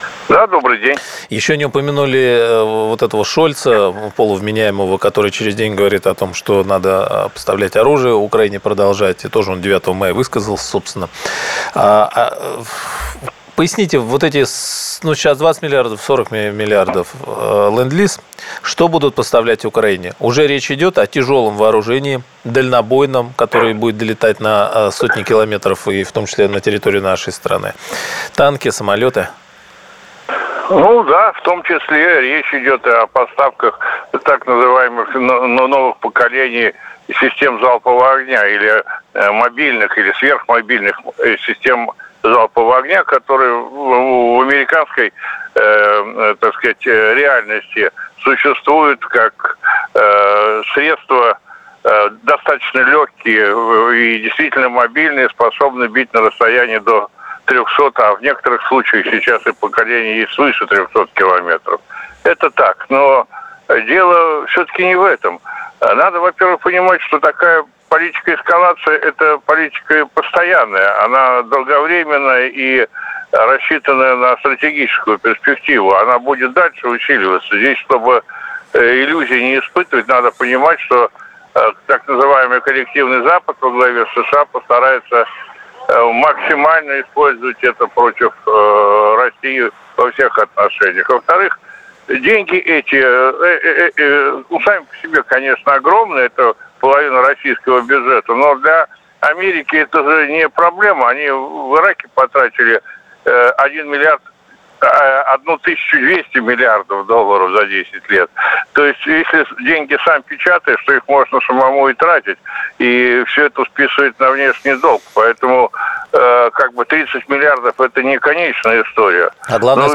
Директор ЦВПИ МГИМО в эфире “Радио КП”